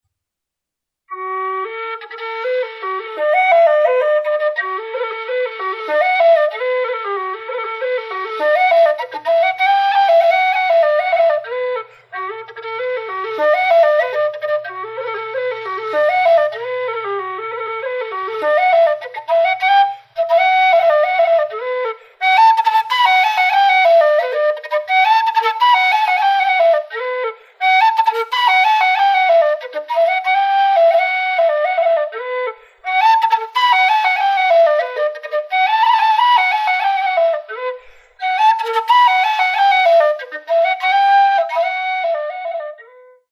bagpipe mp3